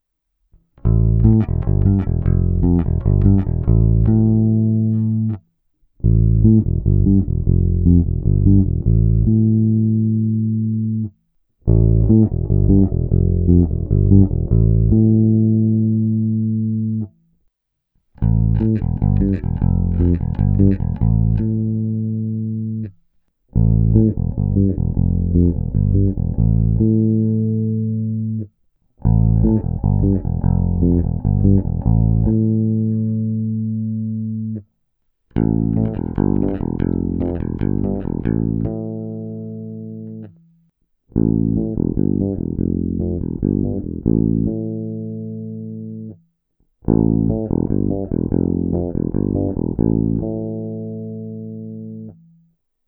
Nahrál jsem k tomu i ukázku, vždy nejdřív s clonou plně otevřenou, pak úplně staženou s 47 nF, a pak úplně staženou s 22 nF. Hráno nejdříve na krkový dělený P snímač, pak na oba, a nakonec kobylkový J singl, vše rovnou do zvukovky.
Ukázka tónové clony
22nF zní pro mě o dost použitelněji.